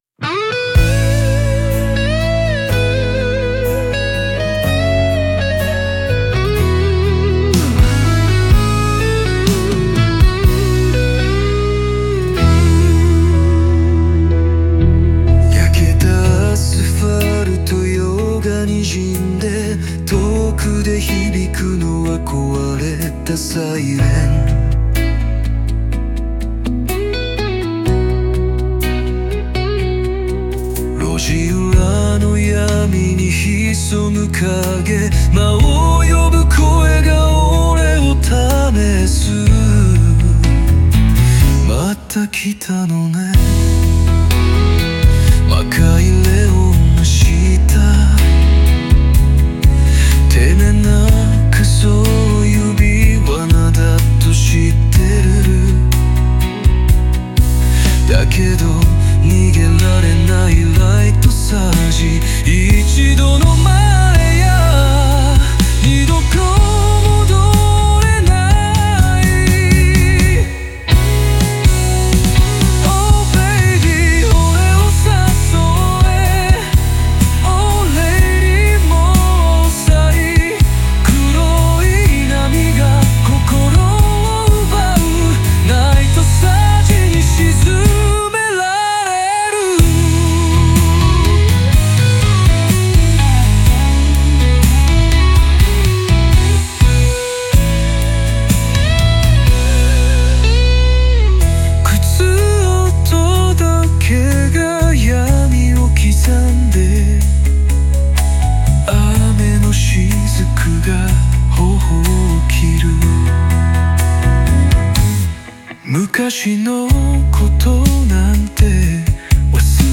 リズミカルなフレーズと繰り返しを多用し、狂おしくも激しい感情の波を音楽的に表しました。